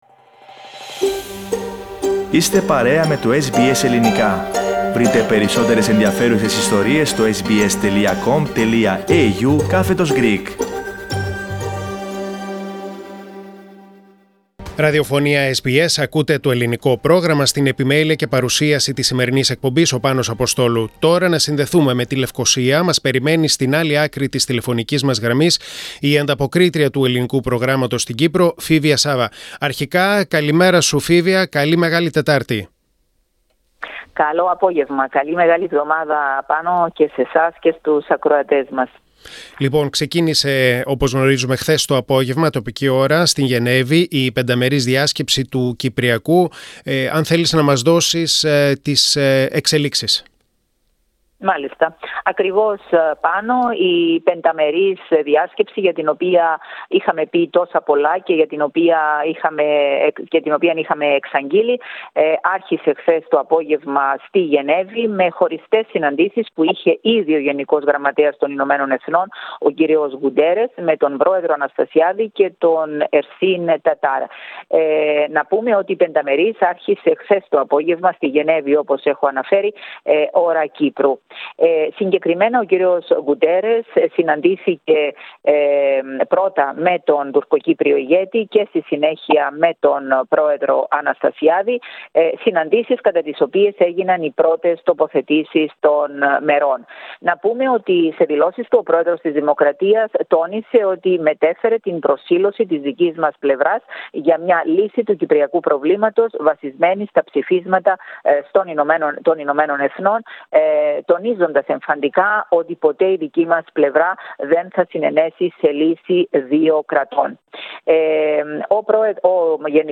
Με χαμηλές προσδοκίες, ξεκίνησε στην ελβετική πόλη της Γενεύης, η άτυπη πενταμερής διάσκεψη για το Κυπριακό. Περισσότερα ακούστε στην ανταπόκριση